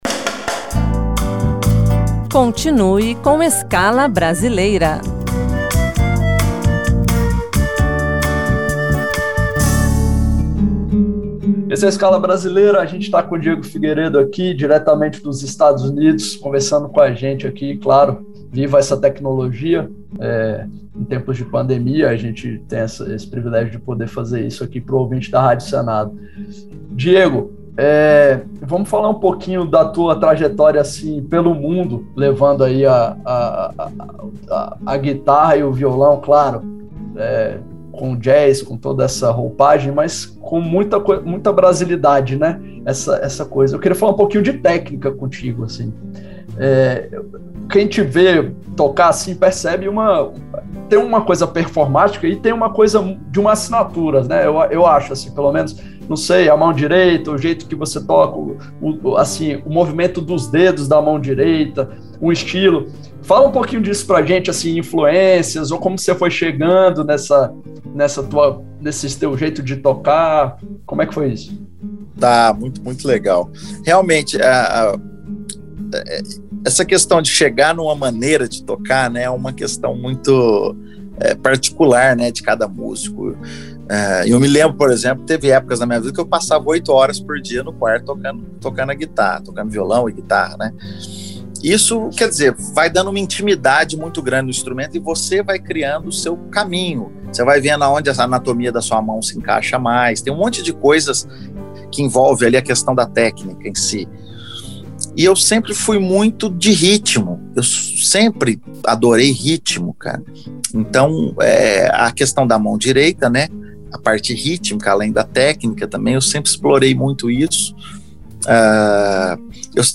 Gênio do violão e da guitarra
música instrumental brasileira